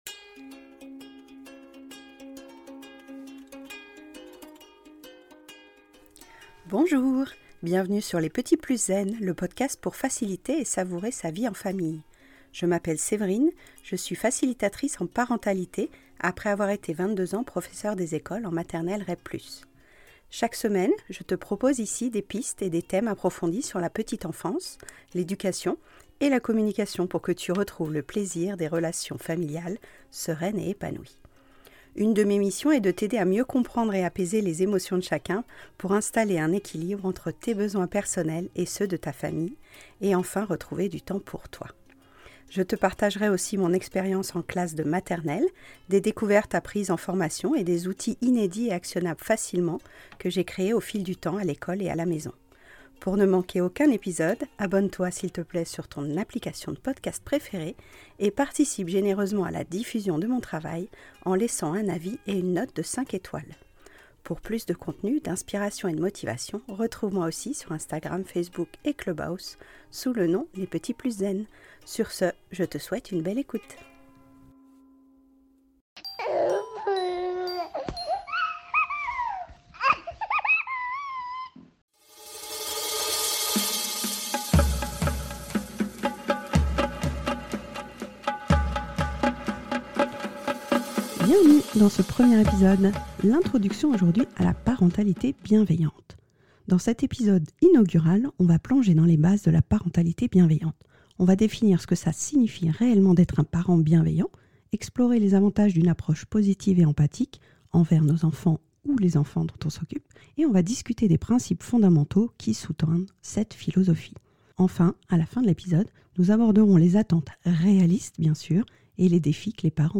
Aujourd’hui, dans cet épisode 154, extrait d'une émission de Radio Ac's, je te détaille comment comprendre et installer la bienveillance en famille par une posture modélisante avec tes enfants.